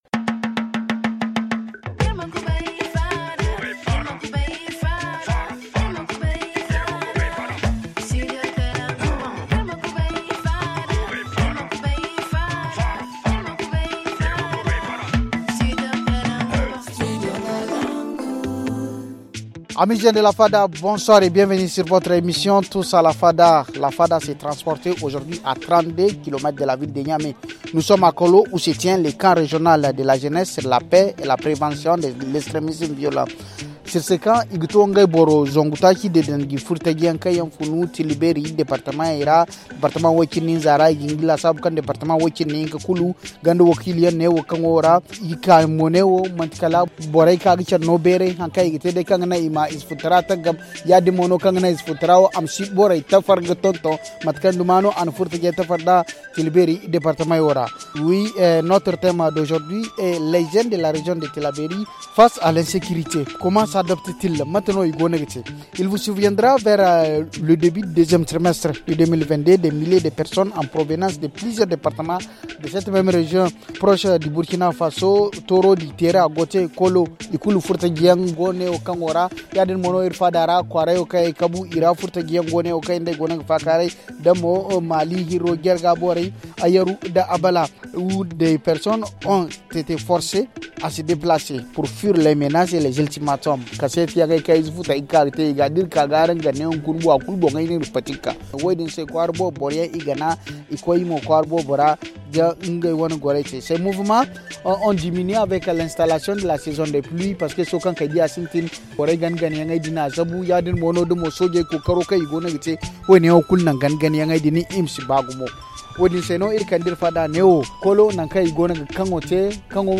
Nous sommes à Kollo où se tient le camp régional de la jeunesse de Tillabéry sur la paix et la prévention de l’extrémisme violent.